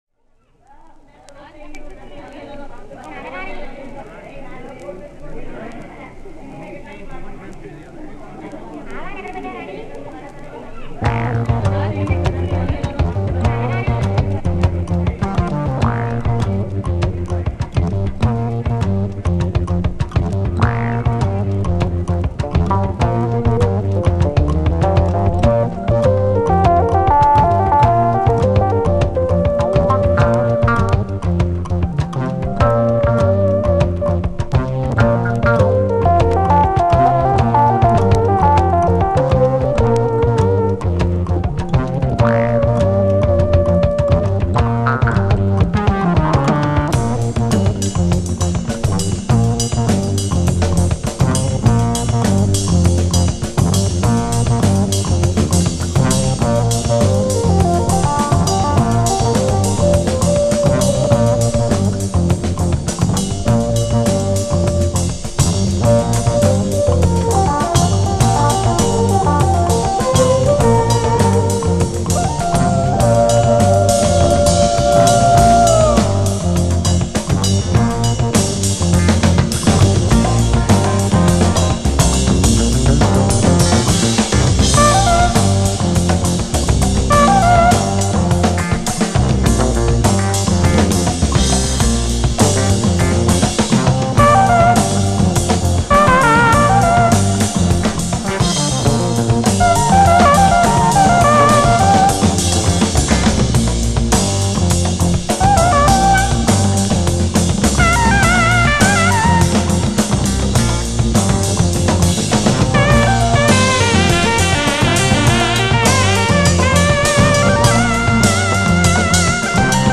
Sigla del GR